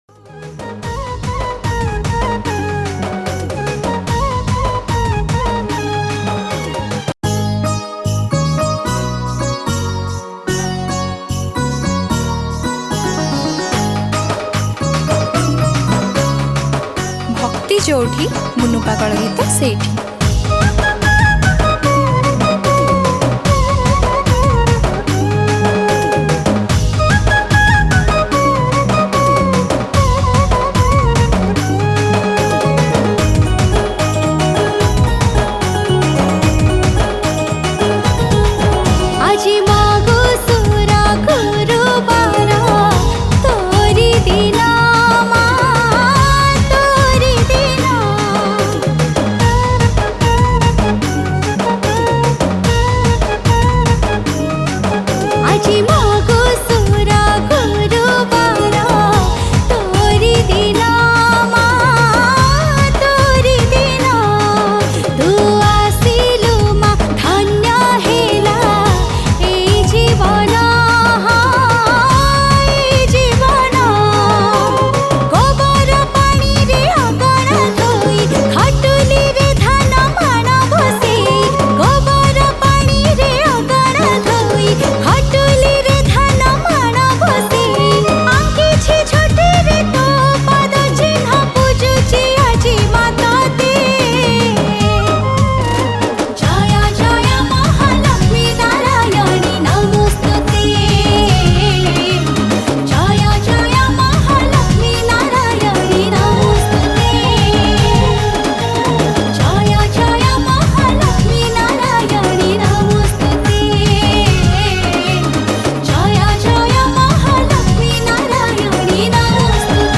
Category: New Odia Bhakti Songs 2022